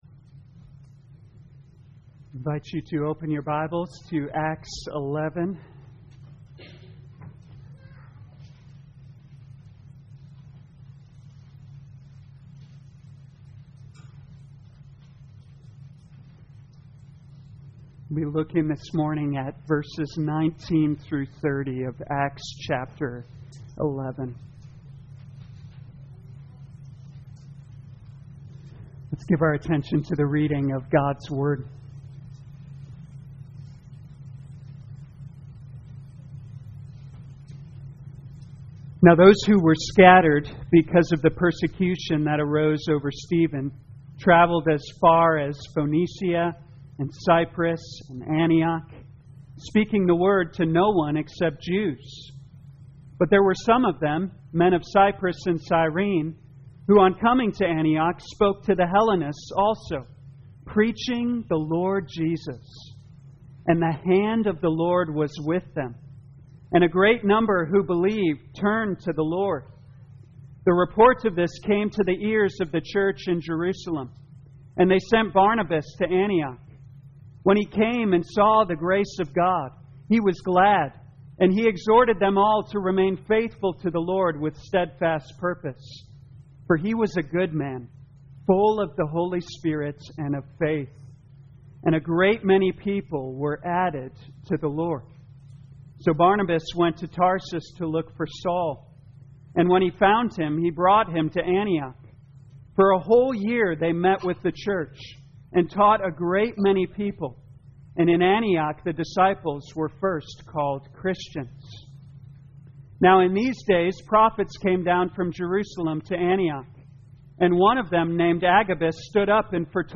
2021 Acts Morning Service Download
You are free to download this sermon for personal use or share this page to Social Media. Kingdom Advance in Antioch Scripture: Acts 11:19-30